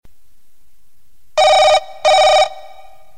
basic_tone.55dd9ff8.mp3